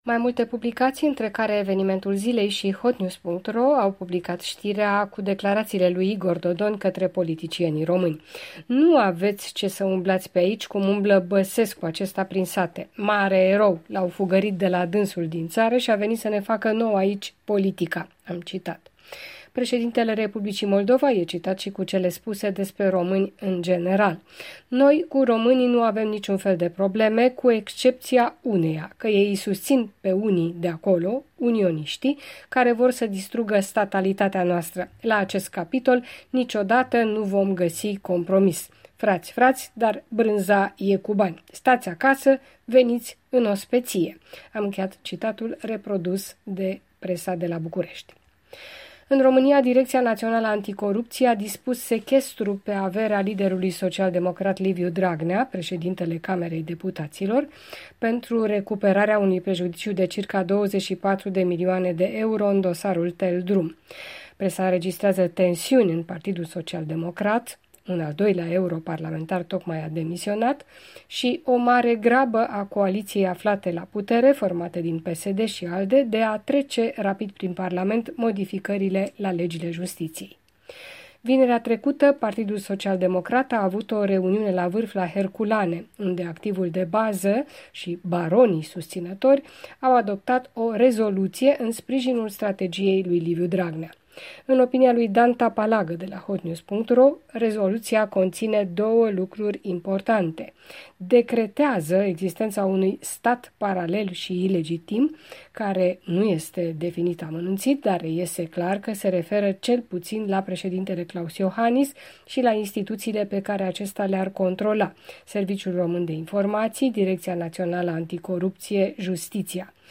Revista presei româneşti.